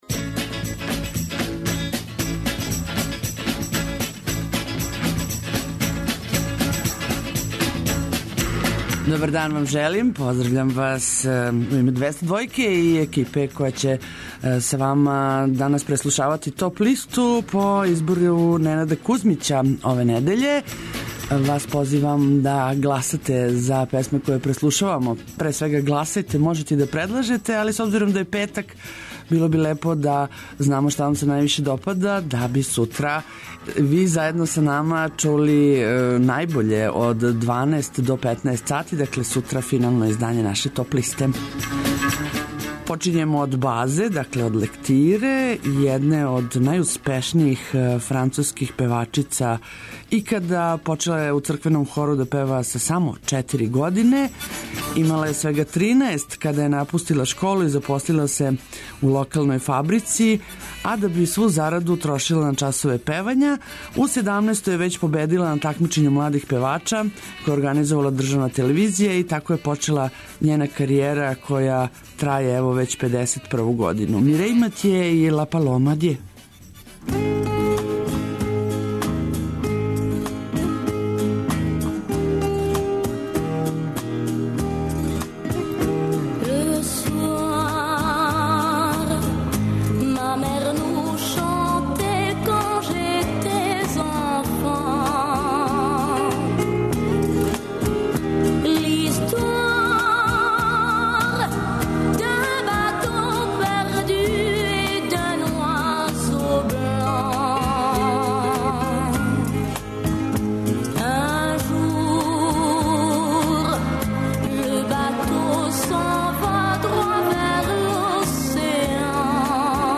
Све то и још много добре музике моћи ћете да чујете у новој Топ листи 202, у првој седмици новембра, сваког радног дана између 9 и 10 сати.